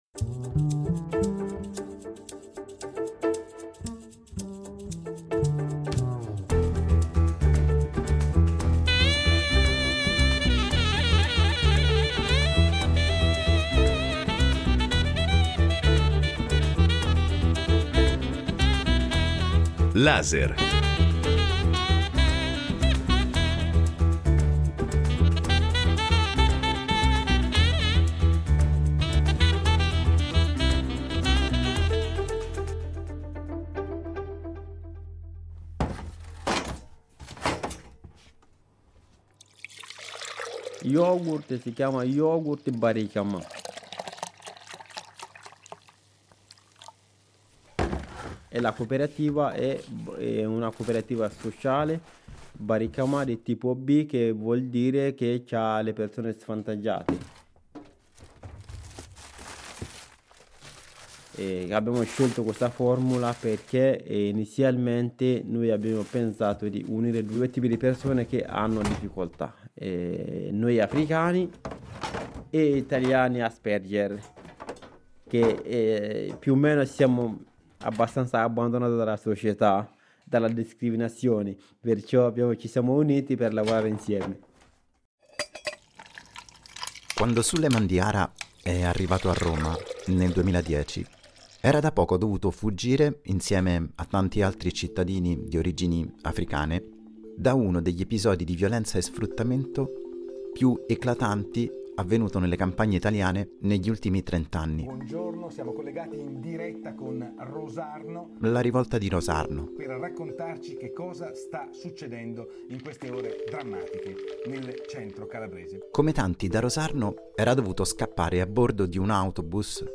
Ripartenza: la parola di gennaio dell’audiodocumentario